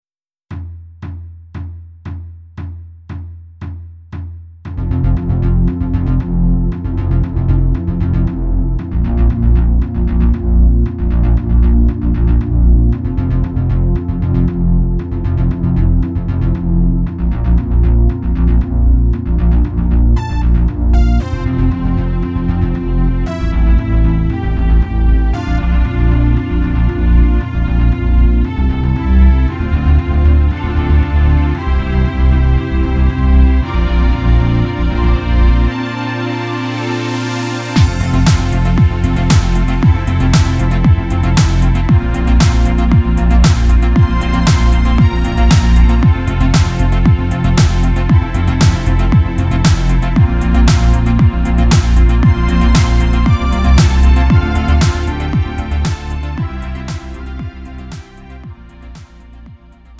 음정 원키 3:43
장르 구분 Lite MR